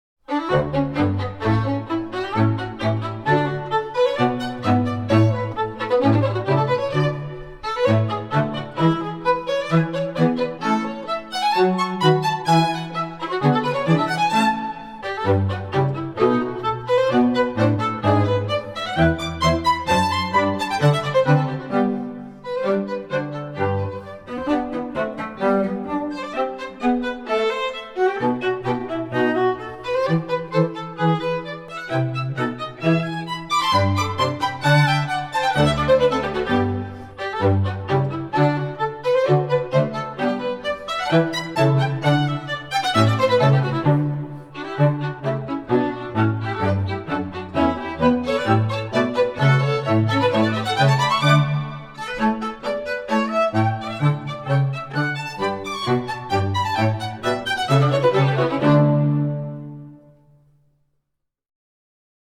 Voicing: St Quartet